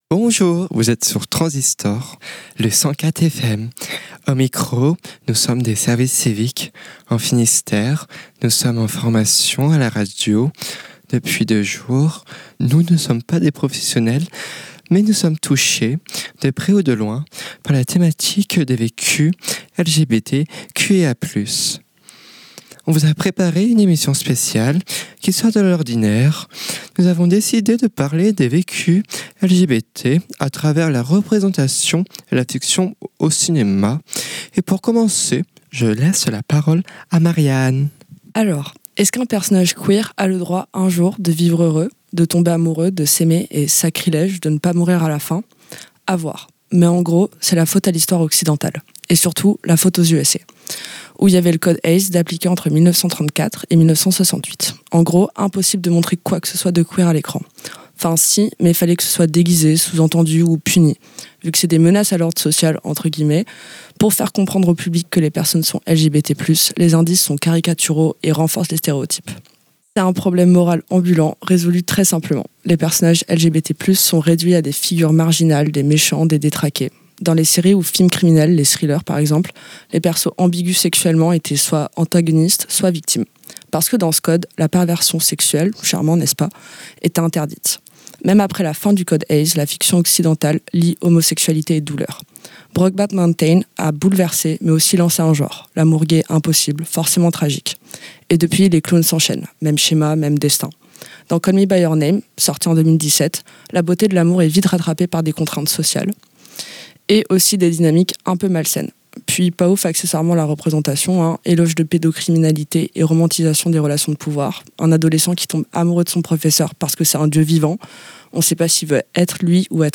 Dans le cadre d'une formation civique et citoyenne, trois jeunes services civiques ont réalisé une émission sur les représentations LGBT+ au cinéma et dans les séries.